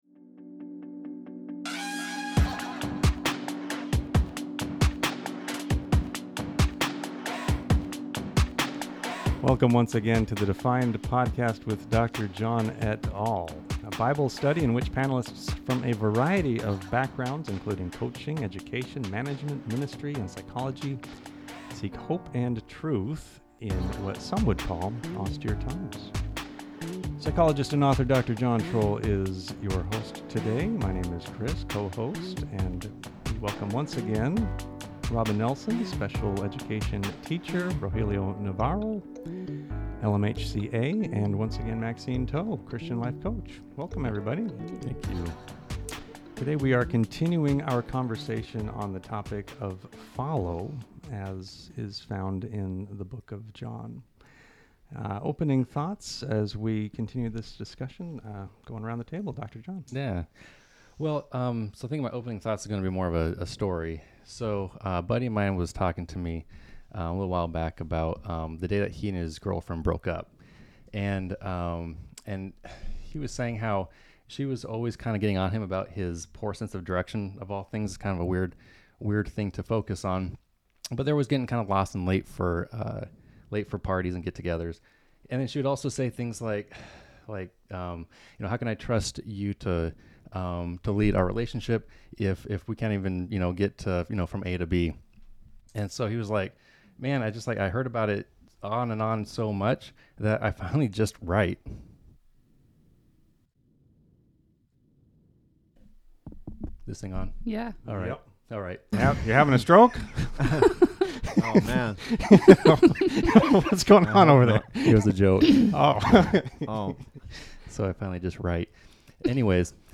S1 E9 | "Follow" Part Two | Bible Study John 1:43-45